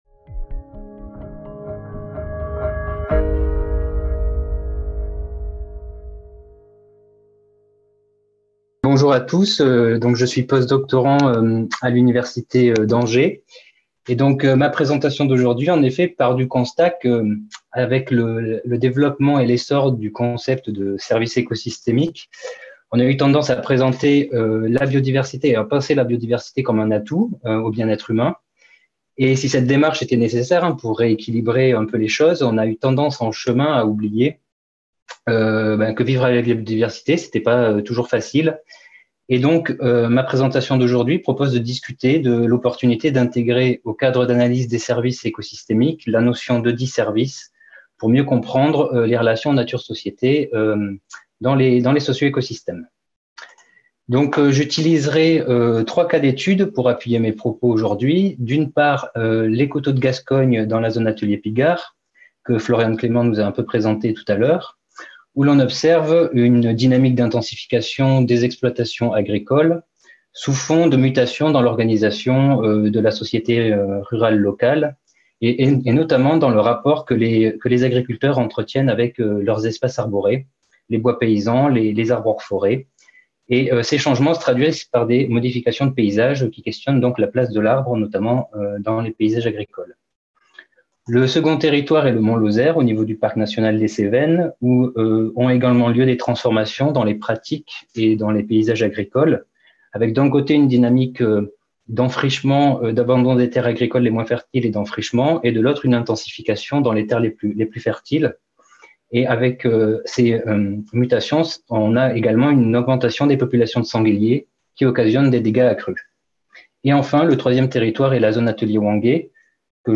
5e colloque des Zones Ateliers – CNRS - 2000-2020, 20 ans de recherche du Réseau des Zones Ateliers